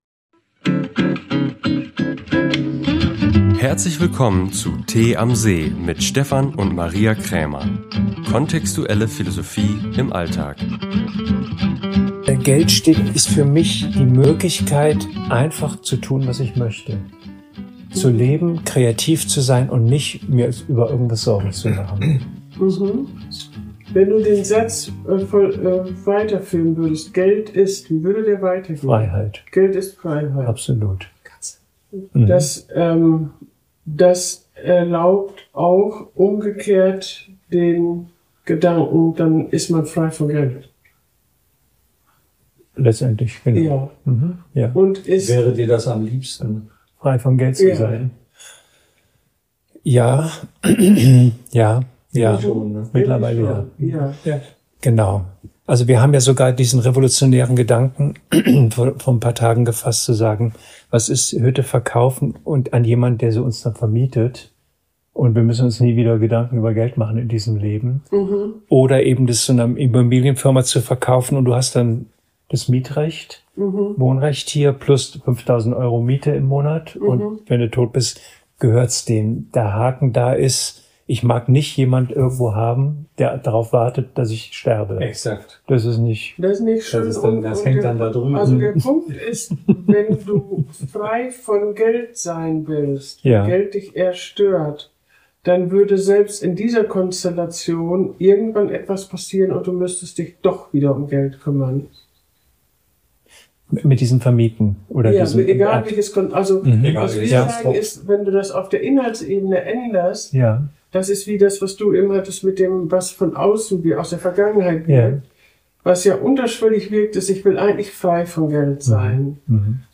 Ein philosophisches Gespräch über den Wert des Geldes.